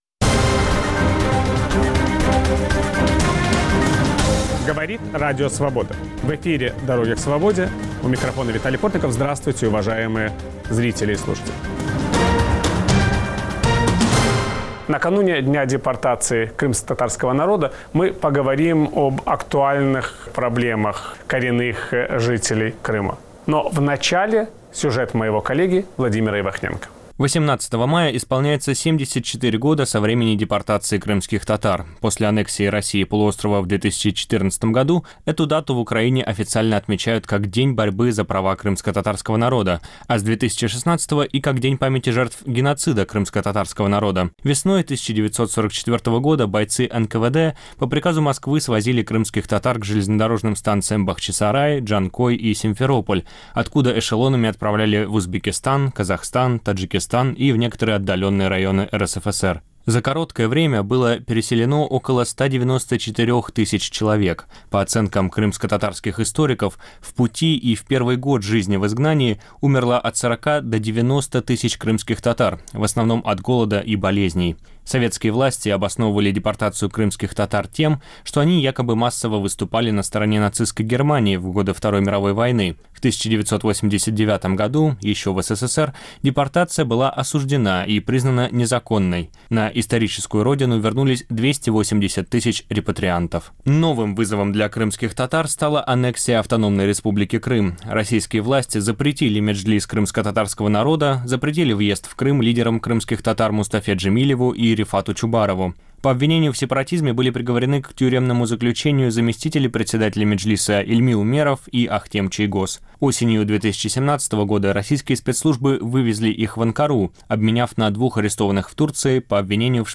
В киевской студии Радио Свобода обсуждаем Украину после Майдана. Удастся ли украинцам построить демократическое европейское государство? Как складываются отношения Украины и России?
Все эти и многие другие вопросы обсуждаем с политиками, журналистами и экспертами.